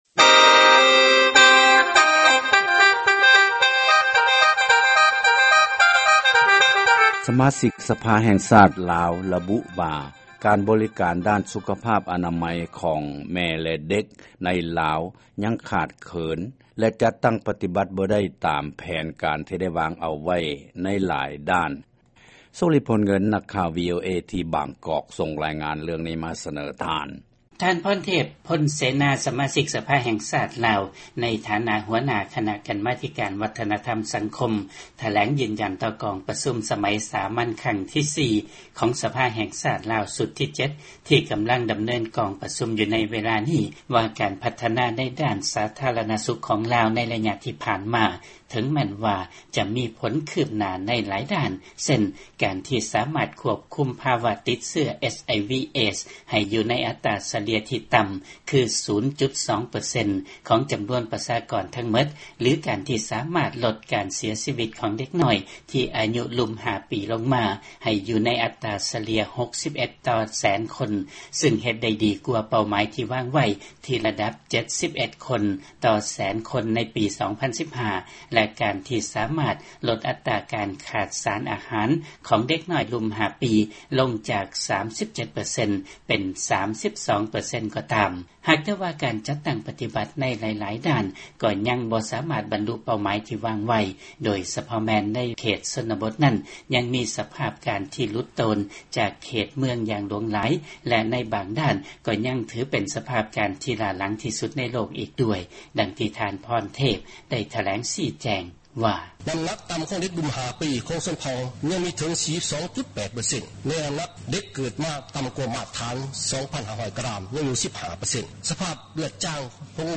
ຟັງລາຍງານຂ່າວດ້ານສາທາລະນະສຸກໃນລາວ